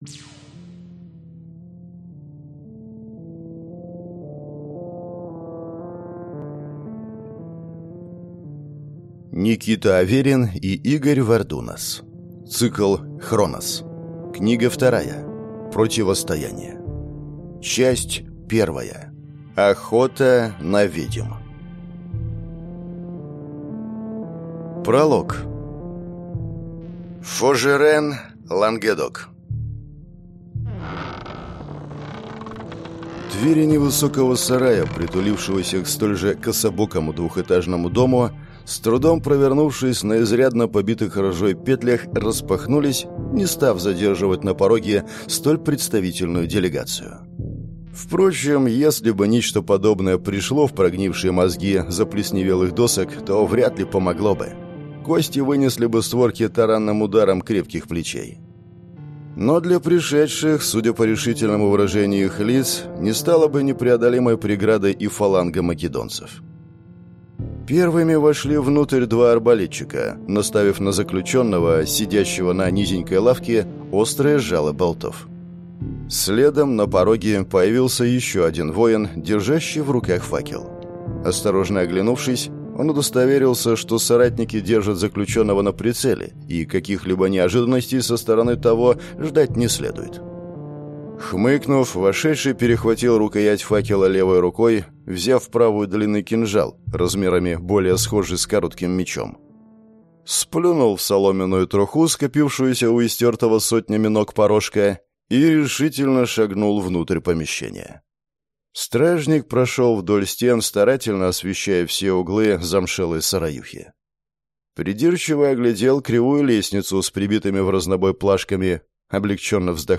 Аудиокнига Противостояние | Библиотека аудиокниг